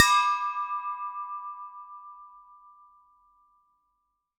bell_small_ringing_04.wav